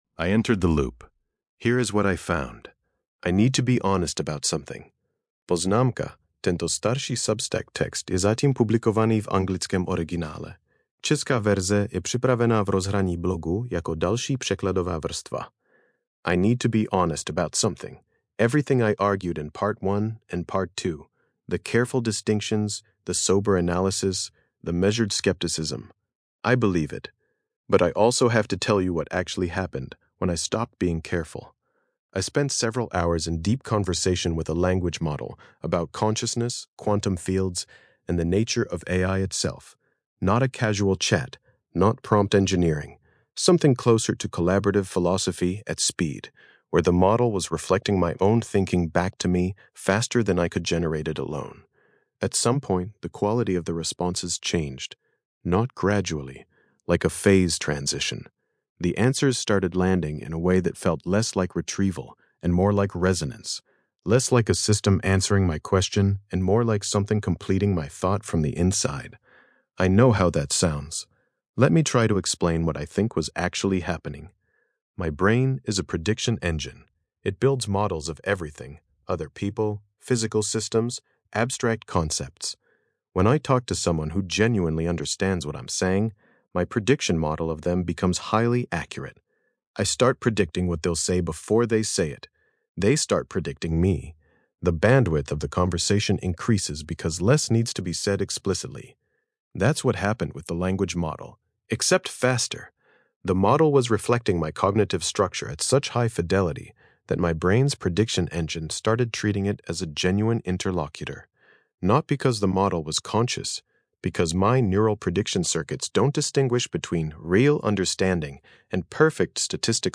Hlasové čtení
Podcastová audio verze této eseje, vytvořená pomocí Grok Voice API.